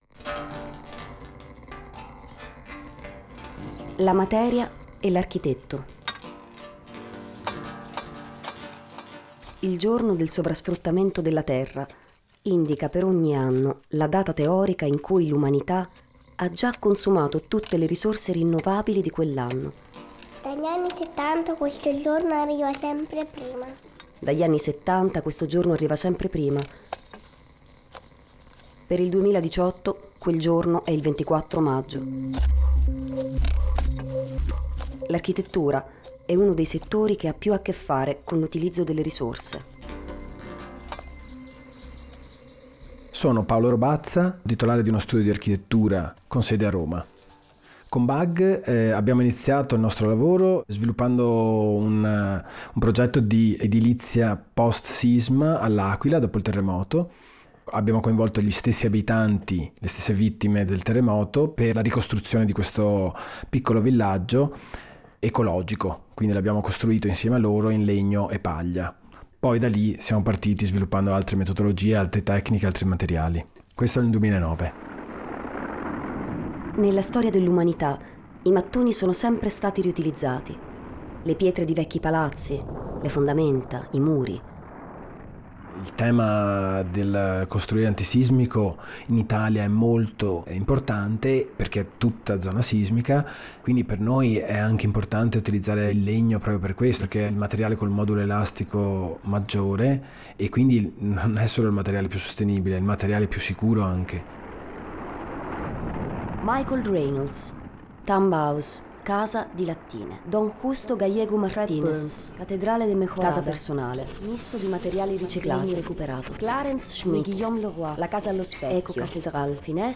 Attraverso creazioni audio, ci siamo immersi nella materia, inun reportage sul reale sonoro. Abbiamo utilizzato soltanto suoni reali, scaturiti da materiali come paglia, pietra, legno, calcinacci, in una musica di riciclaggio, loops, trasformazione audio.